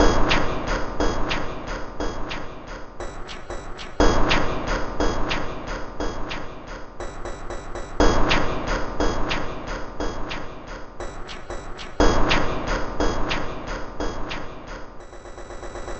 标签： 120 bpm Ambient Loops Fx Loops 2.69 MB wav Key : Unknown
声道立体声